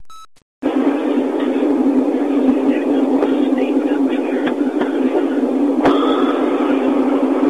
EVP
The voices are frequently whispers and can be intermixed with human conversation.
EVP from Andersonville Prison site.
This EVP was captured by one of the holes.